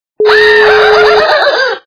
Смех - придурка Звук Звуки Сміх - придурка
» Звуки » Смешные » Смех - придурка
При прослушивании Смех - придурка качество понижено и присутствуют гудки.